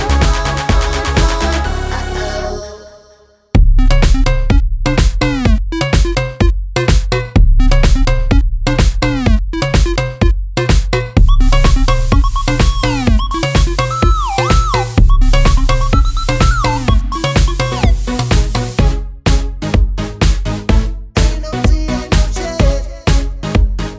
no Backing Vocals Dance 3:16 Buy £1.50